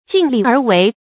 盡力而為 注音： ㄐㄧㄣˋ ㄌㄧˋ ㄦˊ ㄨㄟˊ 讀音讀法： 意思解釋： 盡：全部用出；為：做。用全部力量去做。